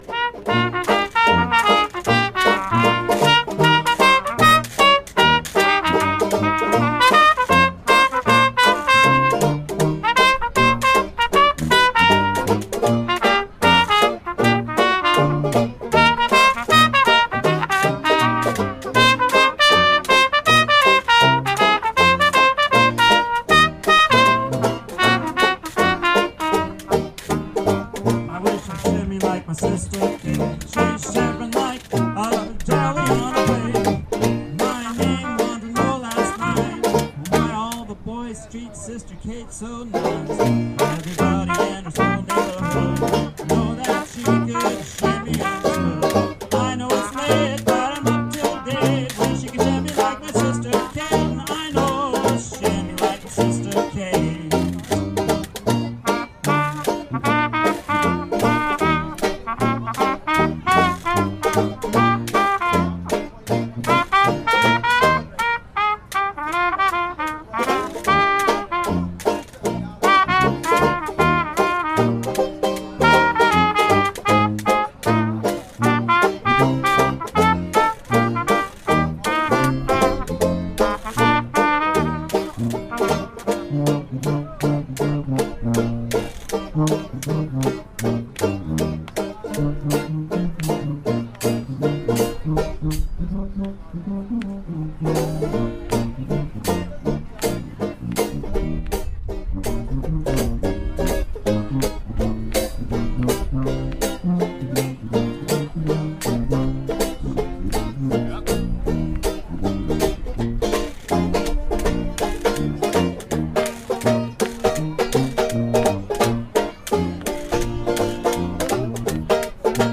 Banjo
Cornet
Trumpet
Tuba
Washboard